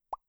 gun_shoot.wav